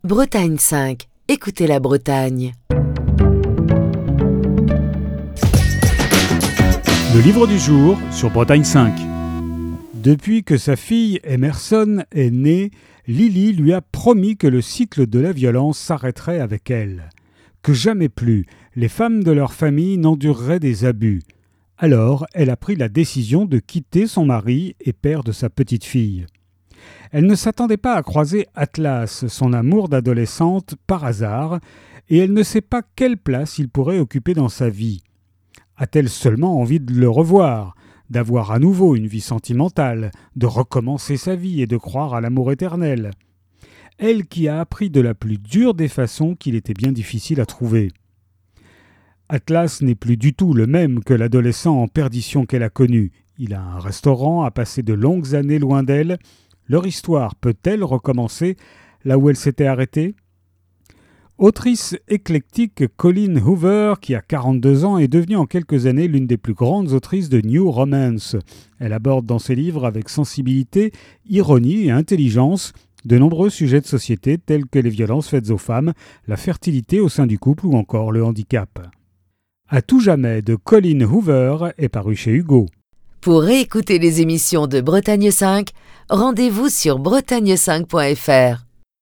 Chronique du 17 février 2023.